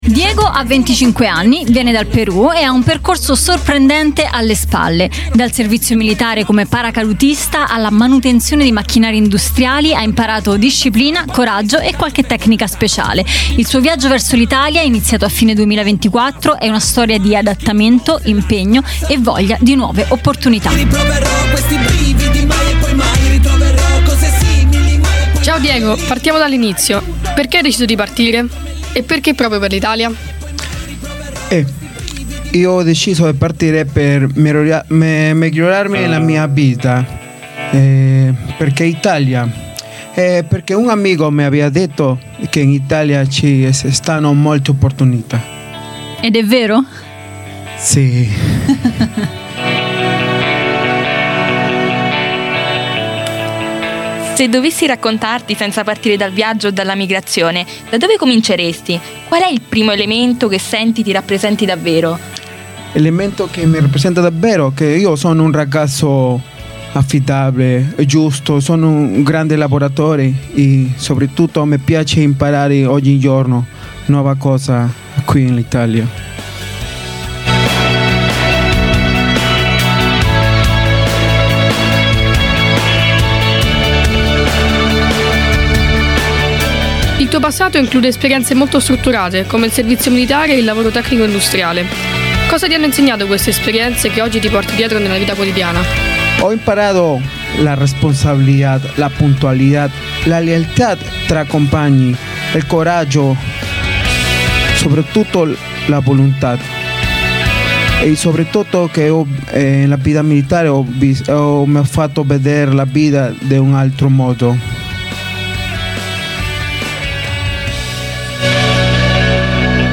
La Giusta Frequenza - Speciale LGNET - Intervista